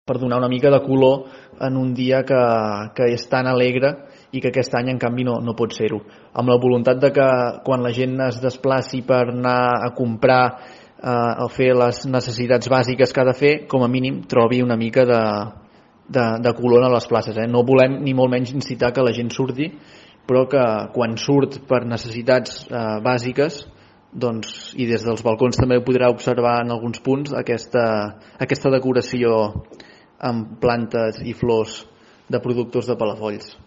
Freixa assegura que la intenció no és, en cap cas, animar a la gent a sortir de casa, sinó que puguin gaudir d’uns carrers més alegres quan surtin per fer les compres de productes bàsics o les gestions permeses dins la situació actual.